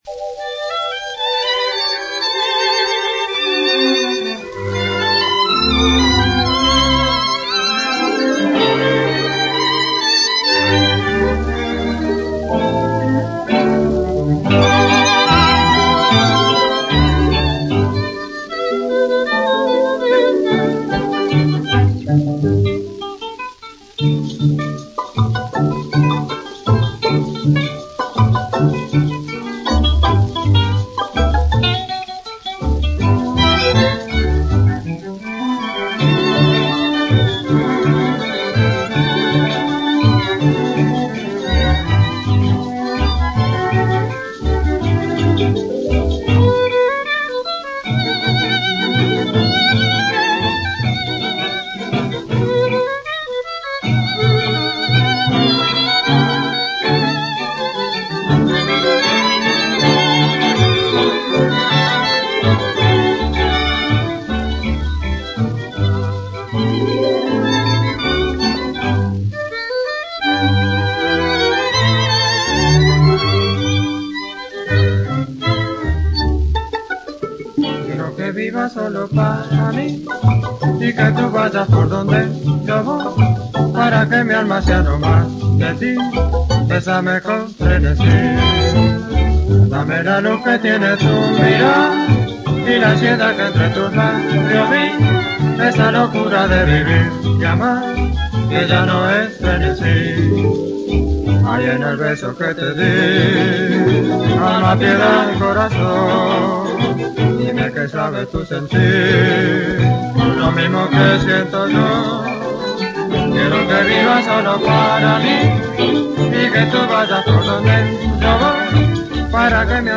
un morceau de « Rumba fox-trot »